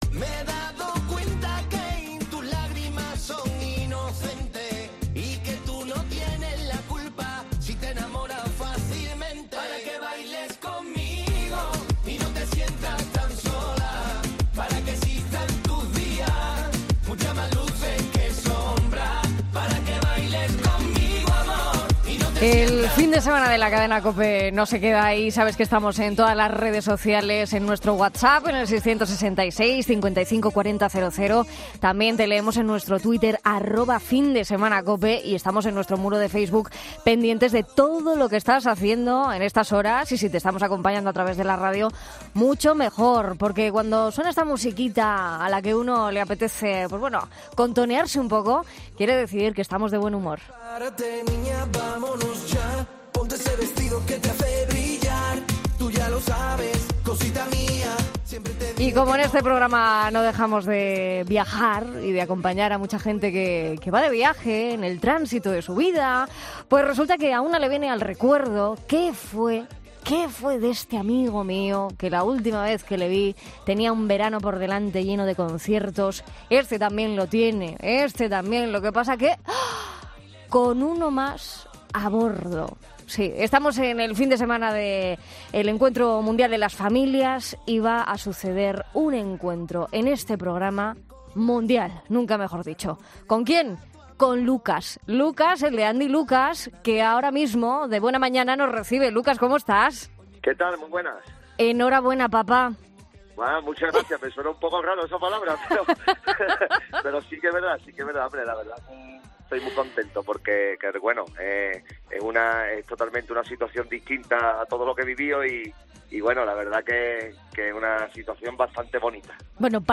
Lucas atiende a 'Fin de Semana' COPE de camino a su próximo concierto junto a su amigo y compañero Andy.